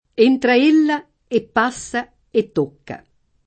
tocco [ t 1 kko ], ‑chi